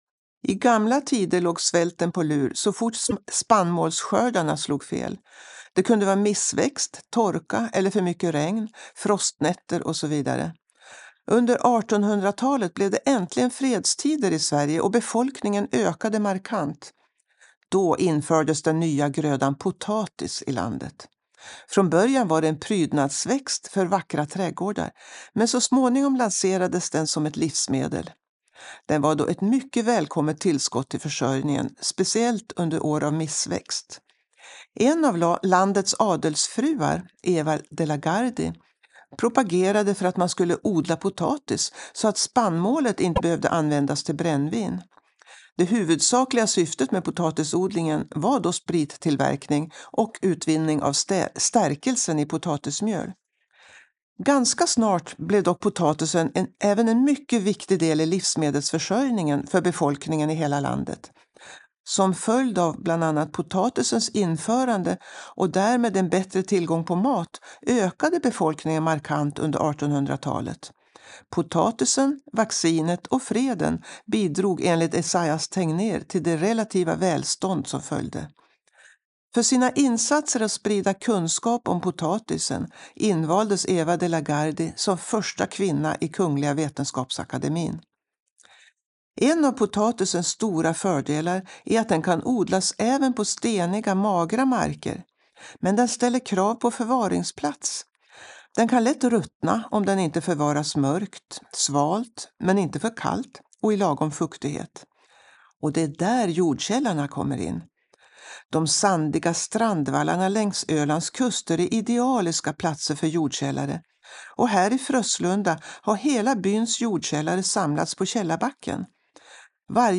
Audioguide med berättelse om jordkällare vid Frösslunda källarbacke